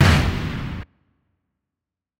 Perc (Boss).wav